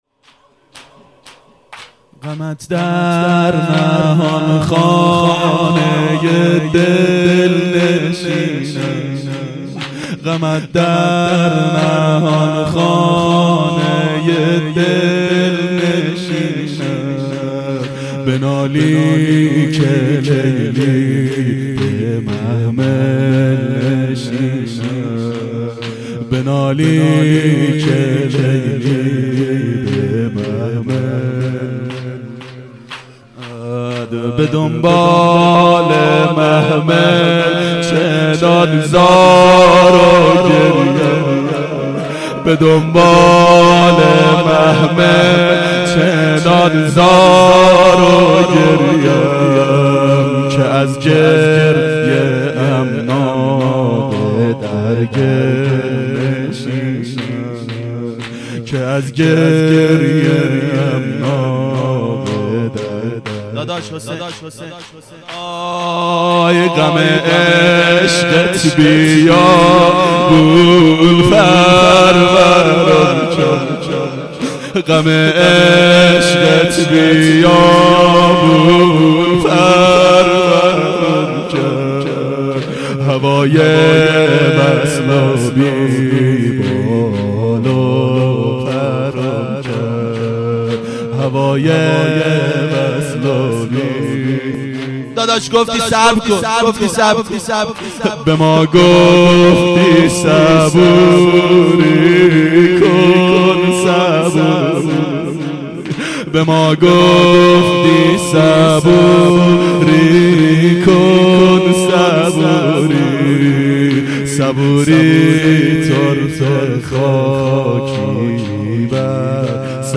ظهر اربعین 1391 هیئت شیفتگان حضرت رقیه سلام الله علیها